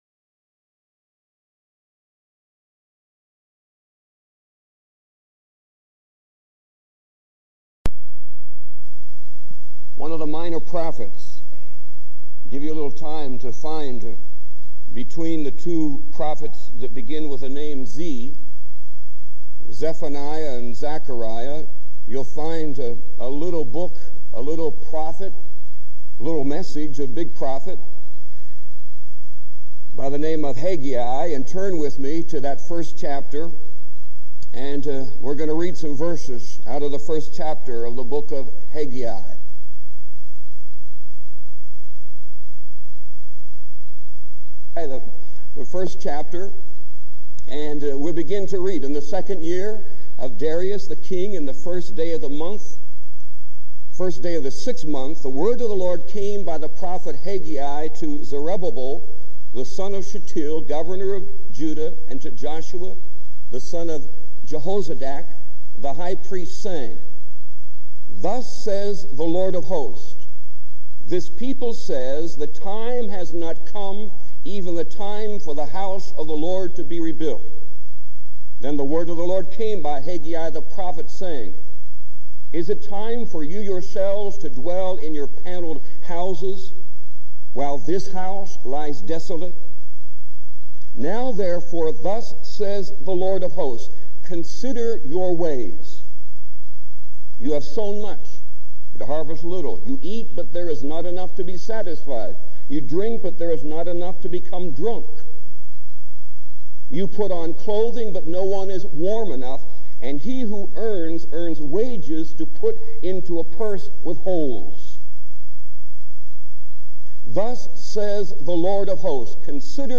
This sermon encourages listeners to be part of the faithful remnant who do not give up in the face of spiritual decline.